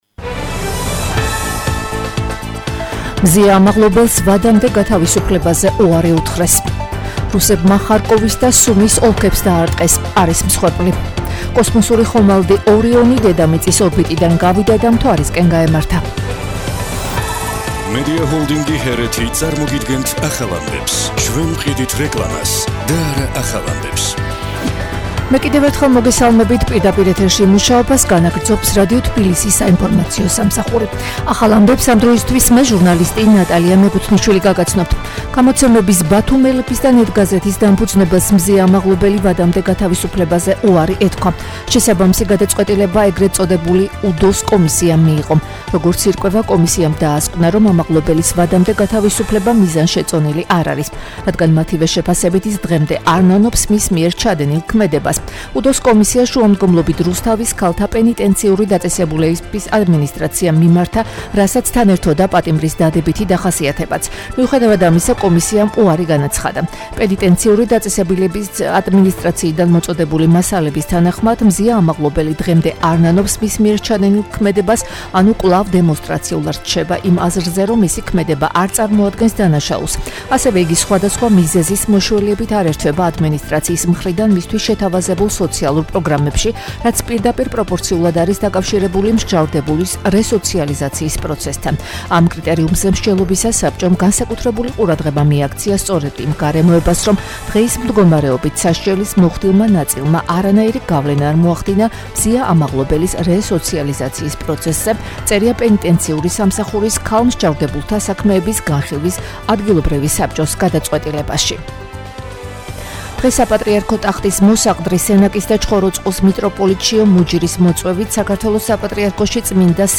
ახალი ამბები 11:00 საათზე